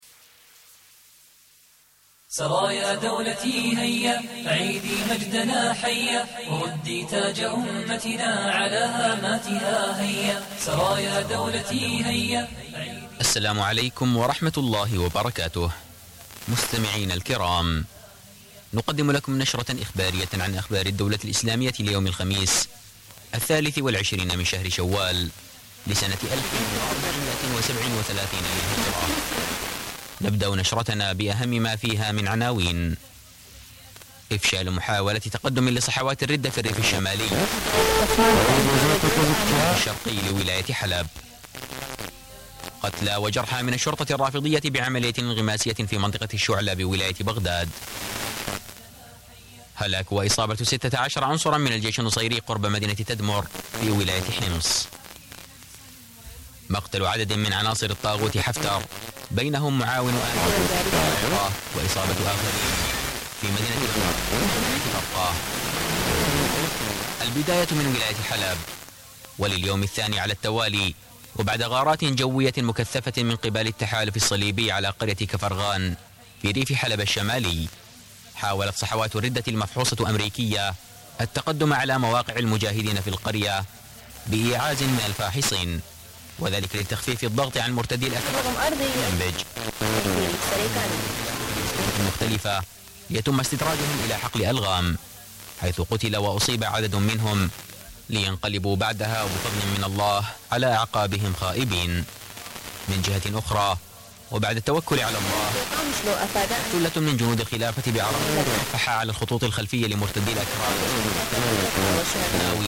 Többször elhangzik a "Dawlah Islamiyah" valamint a tipikus nasheed az elején.